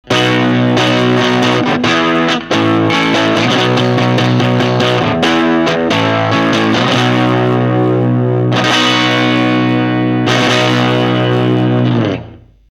おー！ついに劇的な変化が！